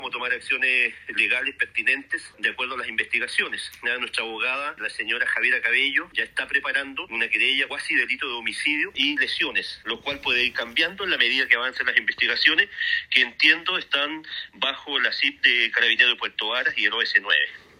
En ese contexto, el alcalde Gervoy Paredes, afirmó que el trámite judicial podría ir cambiando en torno a lo que arroje la investigación.
alcalde-gervoy-paredes1.mp3